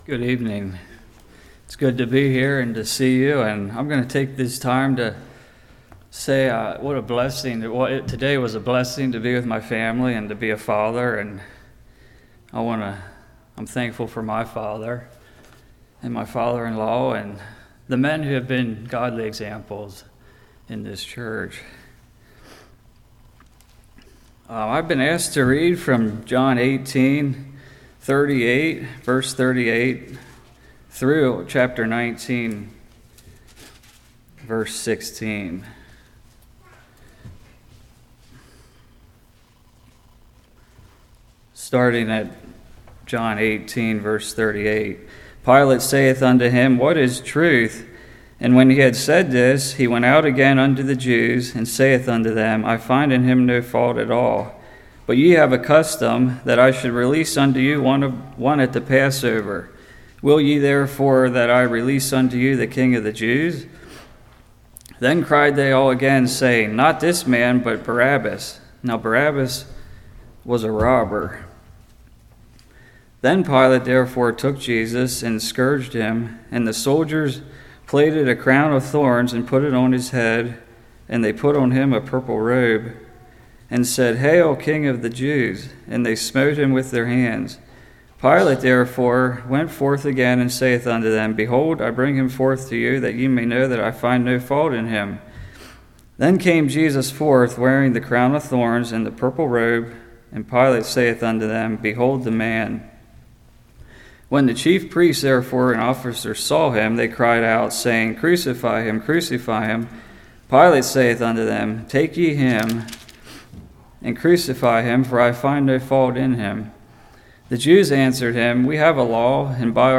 John 18:38-19:16 Service Type: Evening Sinless Innocent Substitute « Aspects From The Life of Jacob Kingdom Series Part 2